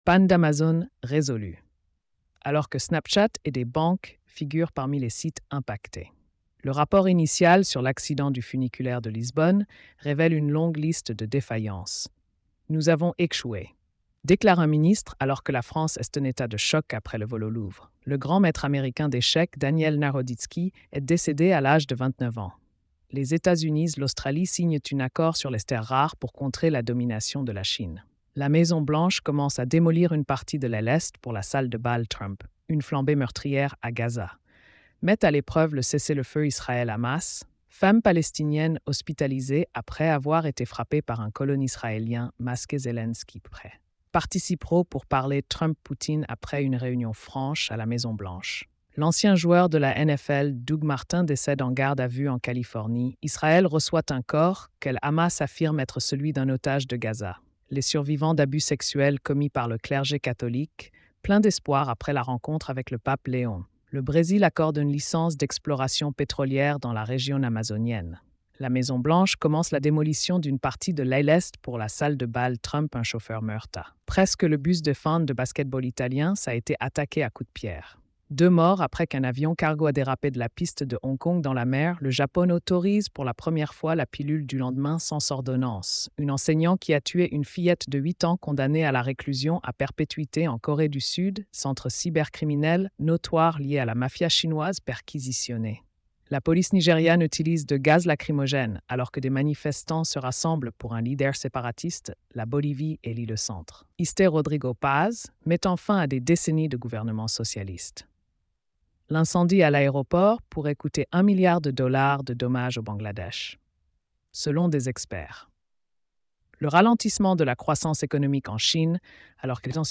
🎧 Résumé des nouvelles quotidiennes.